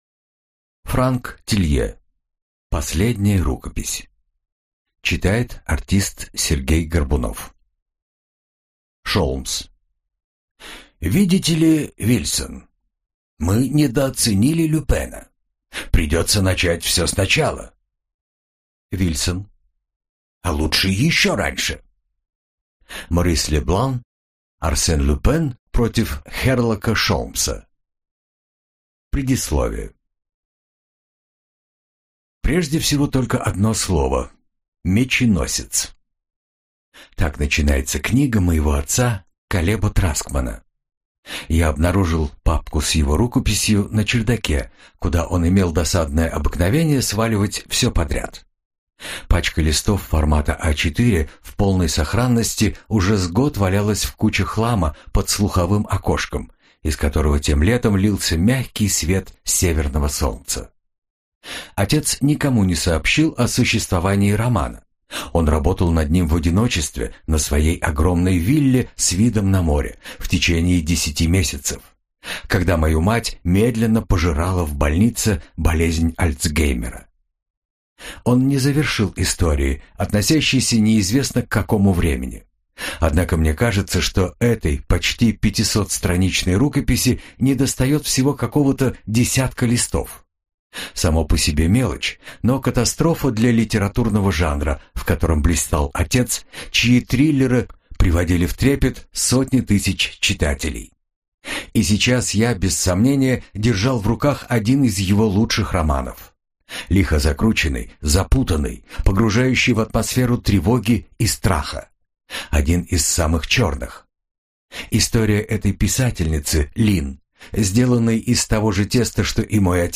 Аудиокнига Последняя рукопись - купить, скачать и слушать онлайн | КнигоПоиск